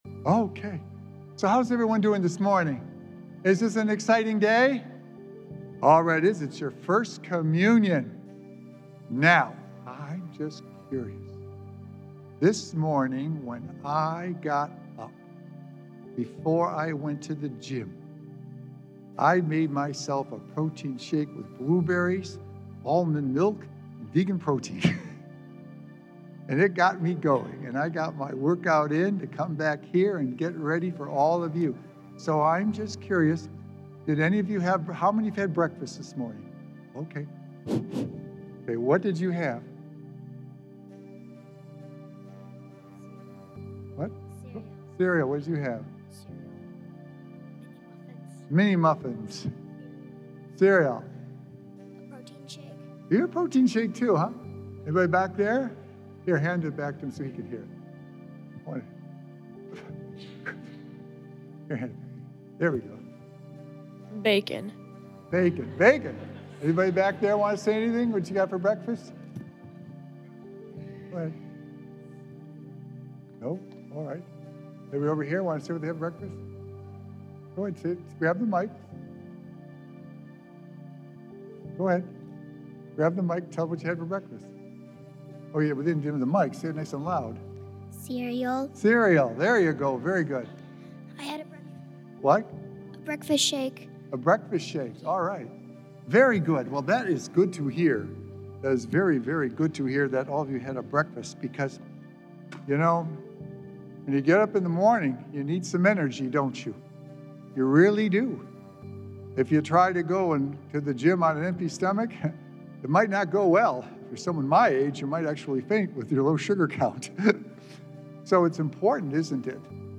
Sacred Echoes - Weekly Homilies Revealed
Jesus gives us the spiritual food we need to strengthen our faith so that we can keep sharing the Good News! Recorded Live on Saturday, May 3rd, 2025 at St. Malachy Catholic Church.